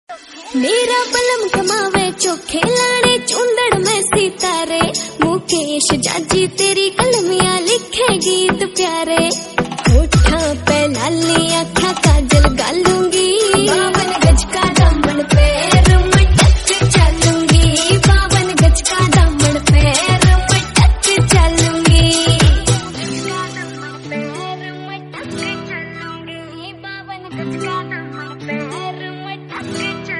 lofi ringtone